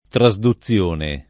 [ tra @ du ZZL1 ne ]